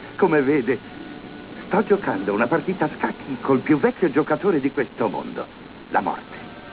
Frase celebre
(Dr. Kersaint)